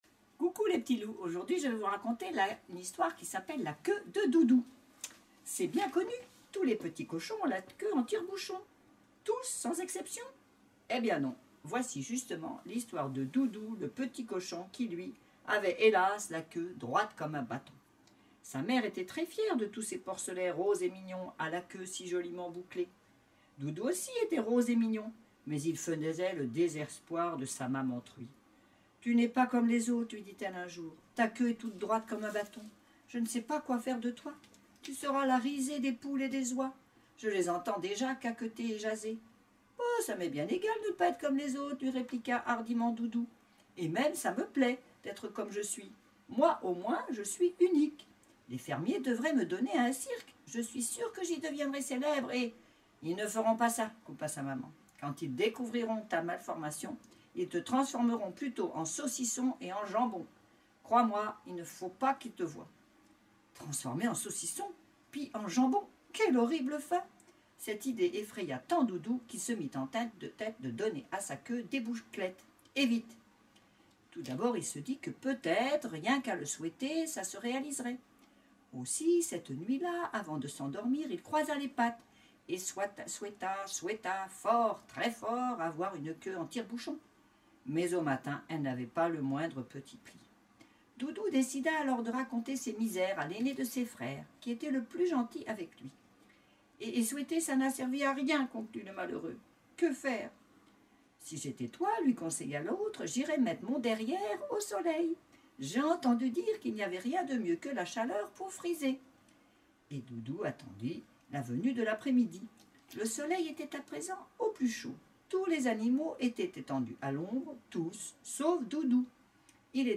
Une histoire racontée